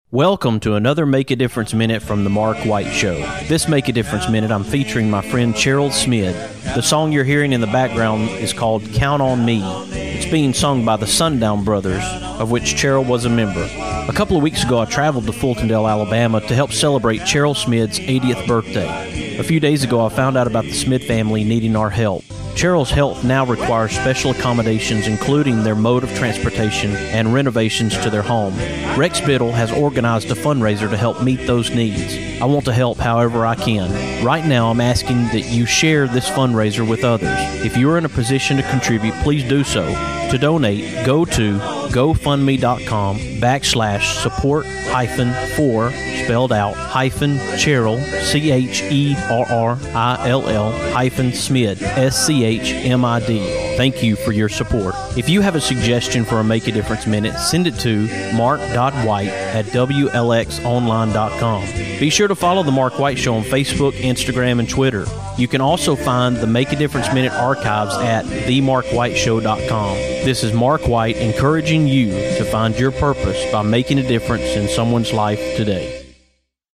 play the song "Count on Me" in the background